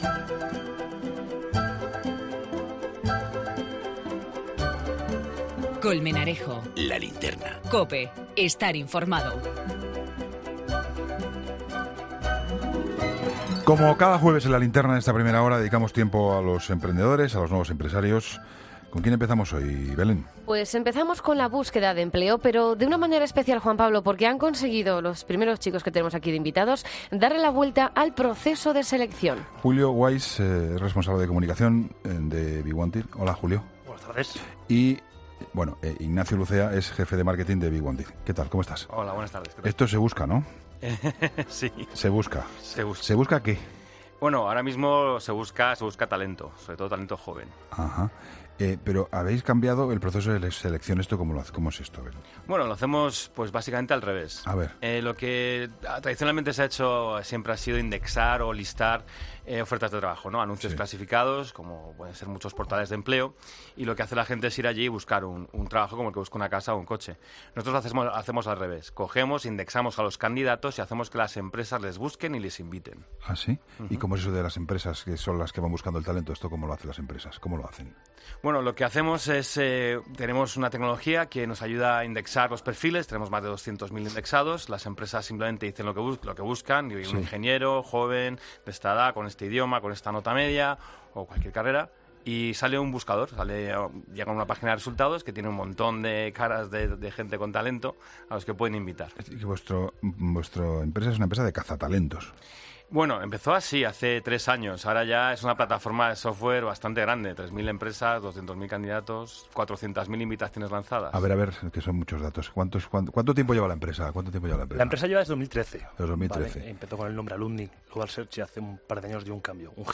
Entrevistas en La Linterna Emprendedores